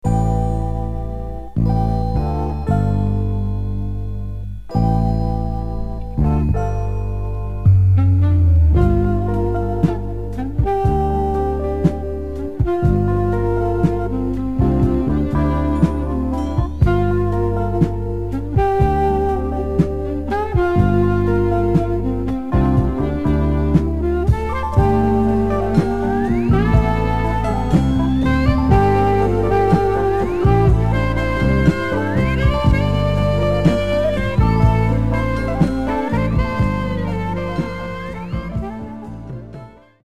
Genre: Soul/Funk